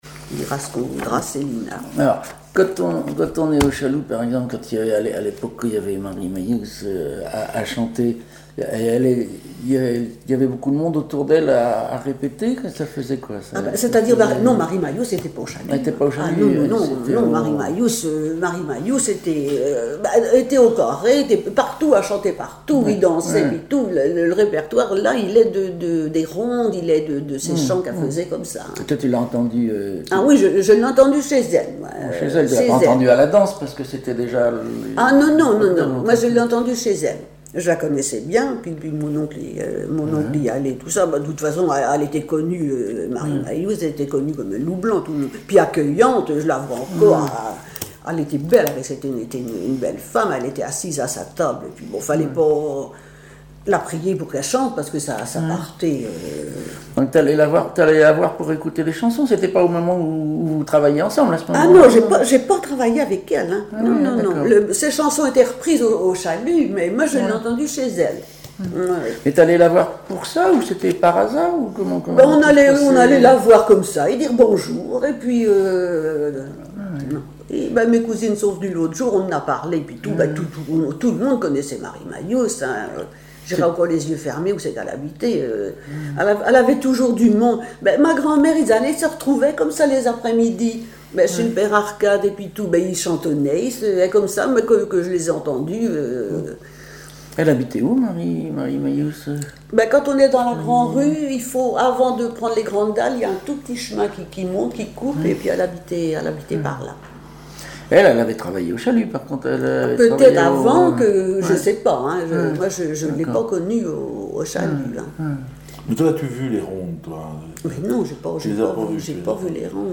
RADdO 085_01_2018_0237te Catégorie Témoignage